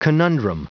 Prononciation du mot conundrum en anglais (fichier audio)
Prononciation du mot : conundrum
conundrum.wav